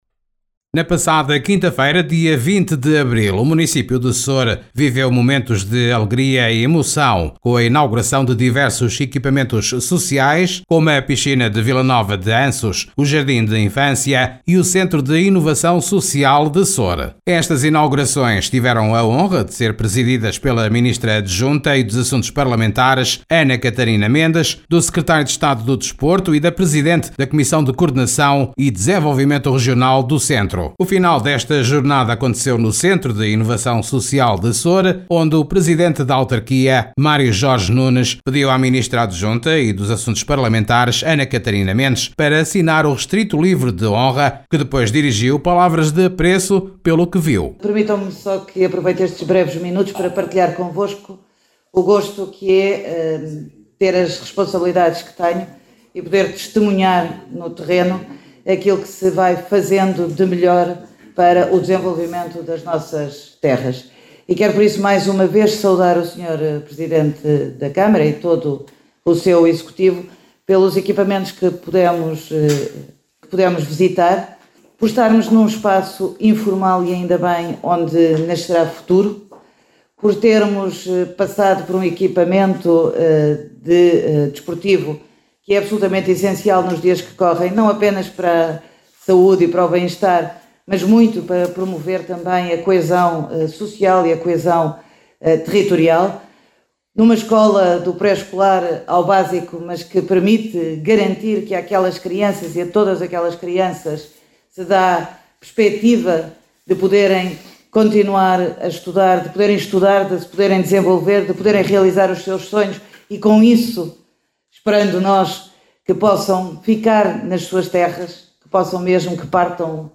Ouça as declarações da ministra Ana Catarina Mendes e do presidente do Município de Soure, Mário Jorge Nunes.